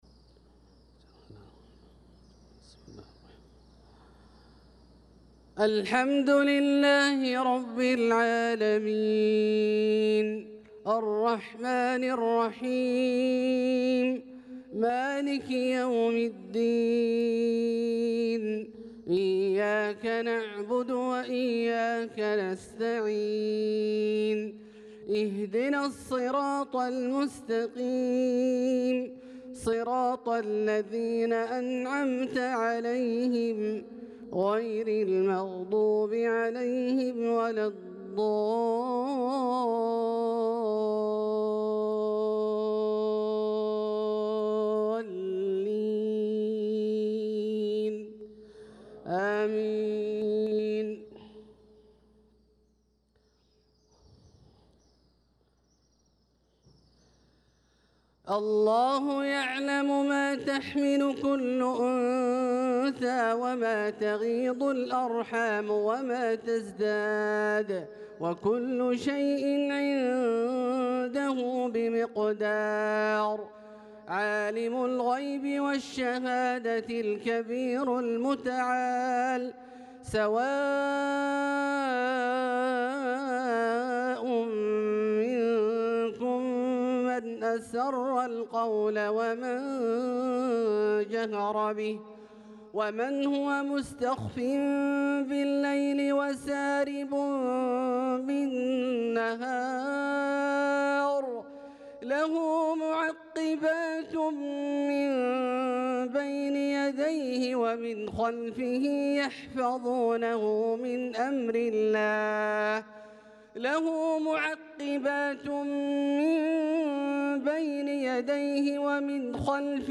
صلاة المغرب للقارئ عبدالله البعيجان 25 ذو القعدة 1445 هـ
تِلَاوَات الْحَرَمَيْن .